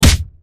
Punch3.wav